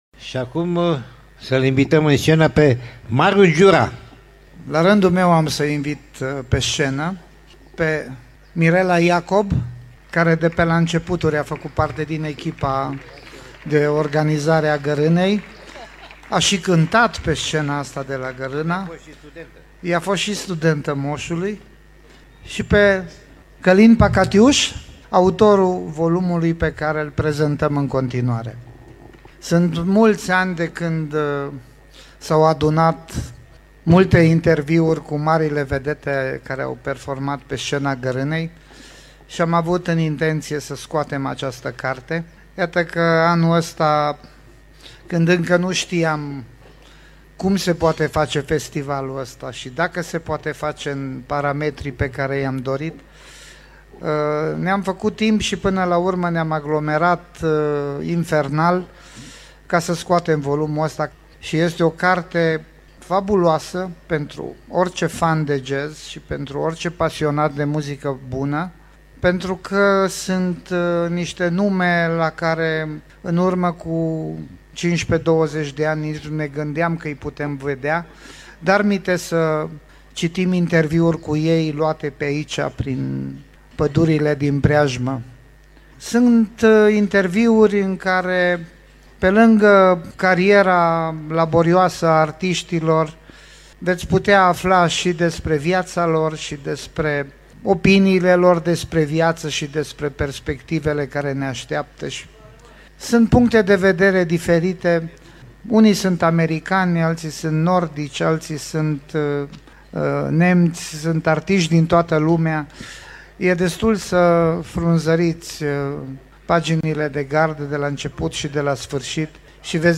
(AUDIO) Lansarea volumului „Gărâna Jazz 25. Interviuri”, pe scena festivalului din „Poiana Lupului, Gărâna, înregistrare de la eveniment, 9 iulie 2021 (Gărâna Jazz Festival, ediția XXV):
Lansare-de-carte-la-Garana-Jazz-Festival-ed.-25.mp3